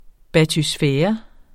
Udtale [ batysˈfεːʌ ]